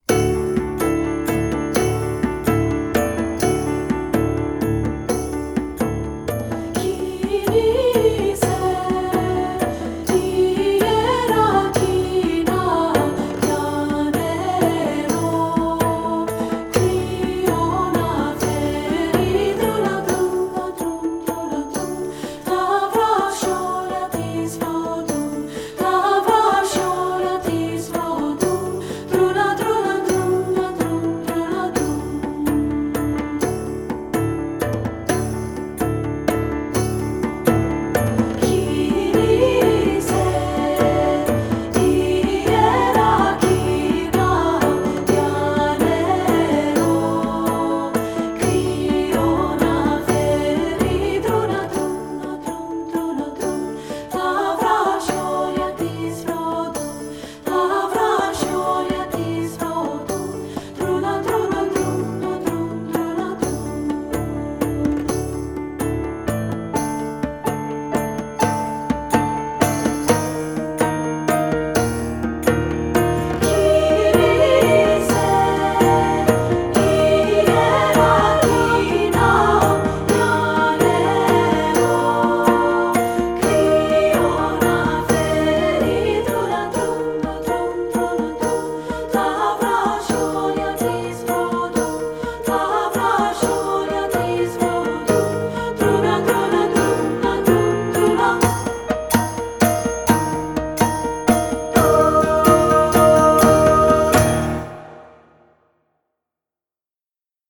General Music Elementary Choral & Vocal Multicultural Choral
the bright 7/8 meter
Greek Folk Song